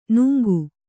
♪ nuŋgu